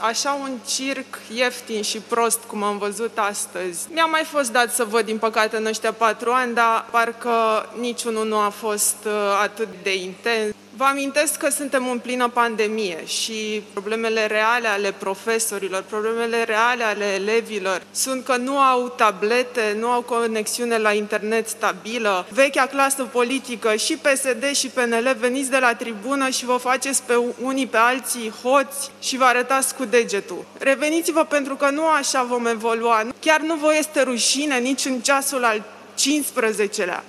Proiectul inițiat de PSD a fost dezbătut, în această dimineață, în plenul Camerei Deputaților, și urmează să primească votul final, în scurt timp.
Deputata USR, Cristina Mădălina Prună le-a cerut să pună capăt circului.